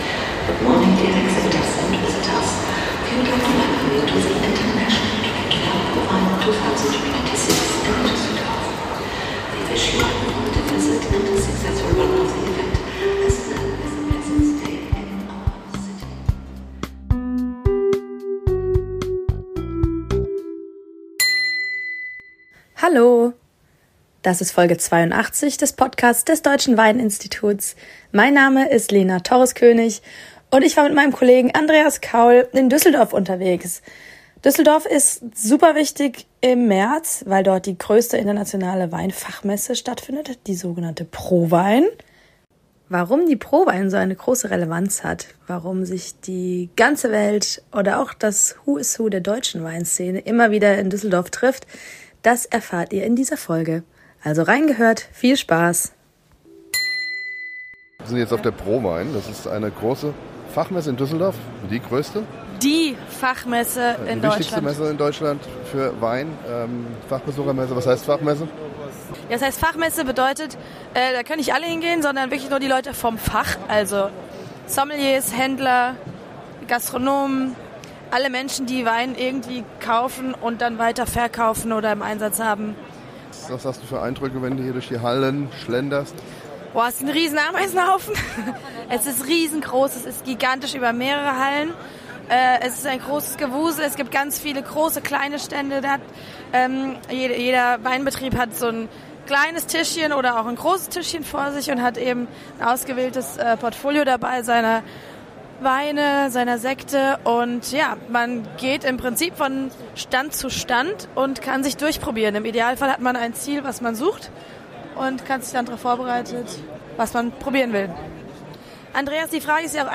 Beschreibung vor 1 Woche Plopp, plopp, pfffff, kling.... ahhhhhh Diese Messe hat es in sich. Wer beruflich mit Wein zu tun hat, kommt kaum an ihr vorbei: die ProWein in Düsseldorf.